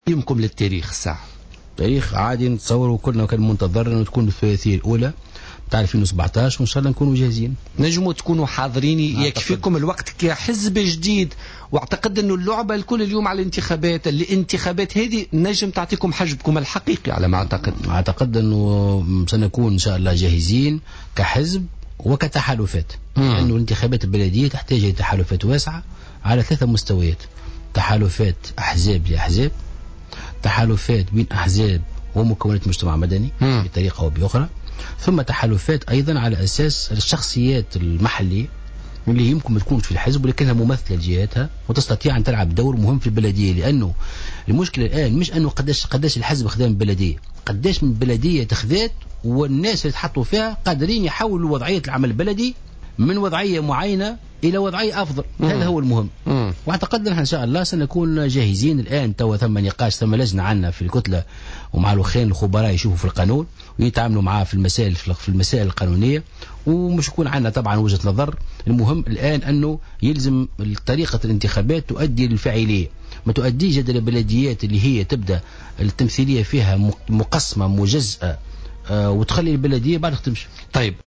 وأضاف مرزوق ضيف برنامج "بوليتيكا" اليوم الاثنين أن هذه الانتخابات تحتاج إلى تحالفات واسعة بين الأحزاب ومع مكونات المجتمع المدني.